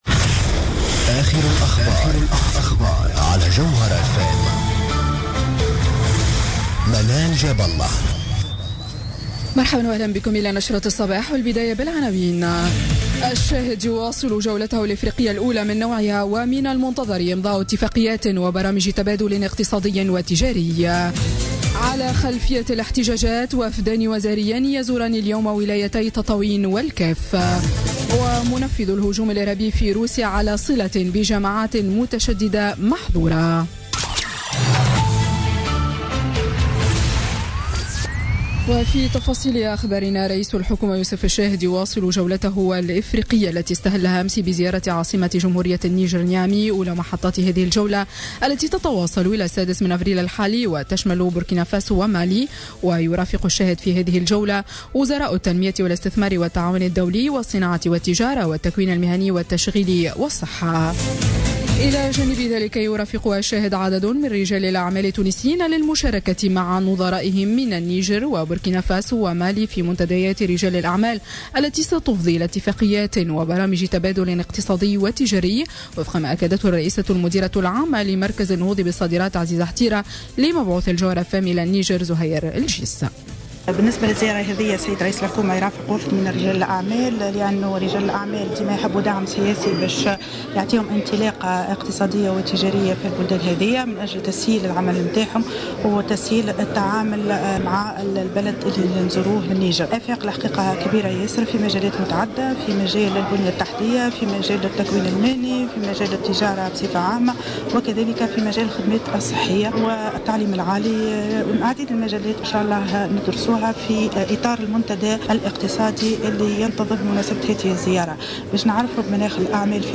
نشرة أخبار السابعة صباحا ليوم الثلاثاء 4 أفريل 2017